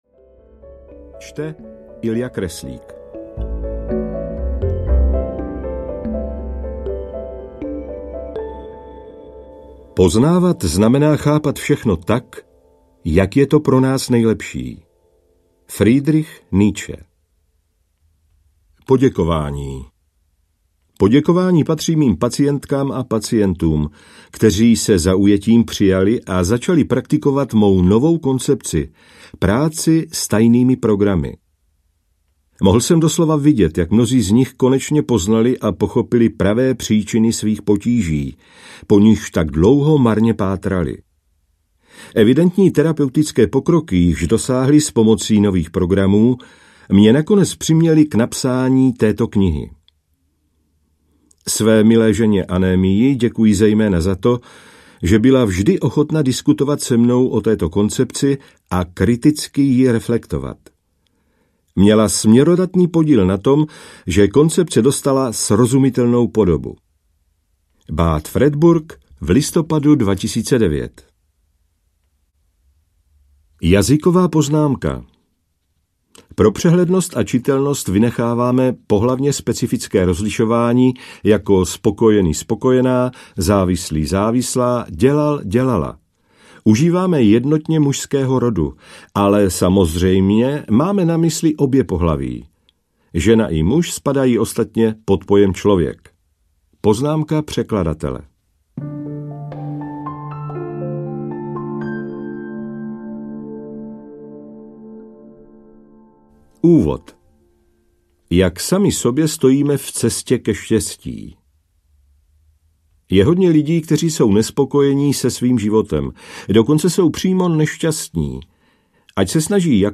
Ukázka z knihy
nedostatecny-pocit-vlastni-hodnoty-audiokniha